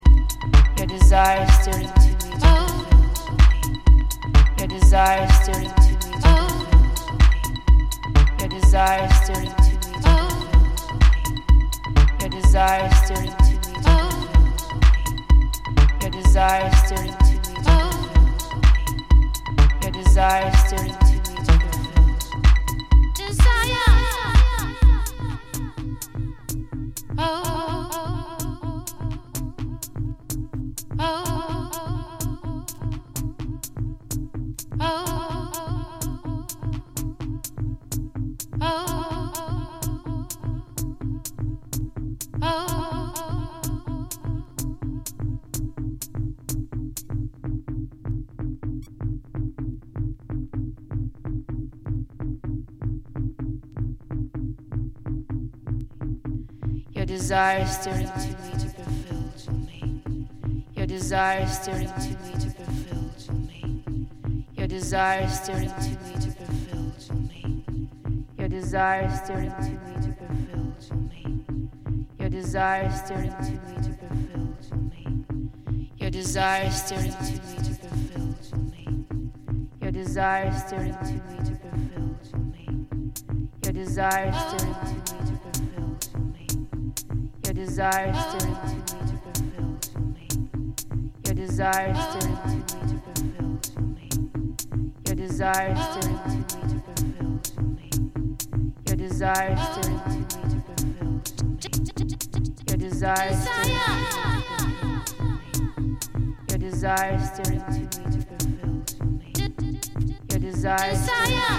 seductive voice surges in and out